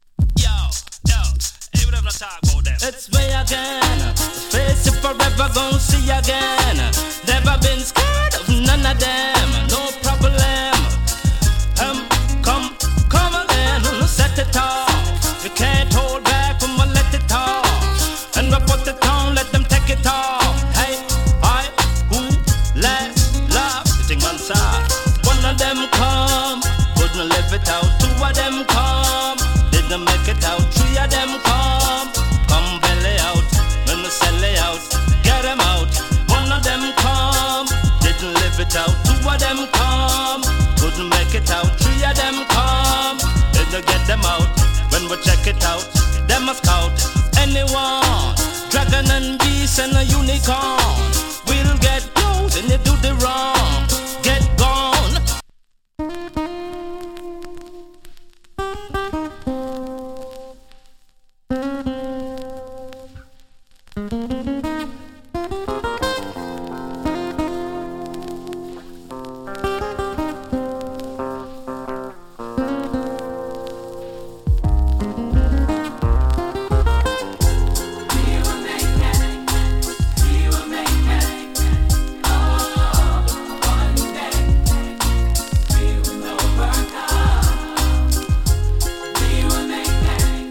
DANCE HALL 00'S
A：VG+ / B：VG+ ＊スリキズ少々有り。チリ、パチノイズ少し有り。
ROOTS VOCAL !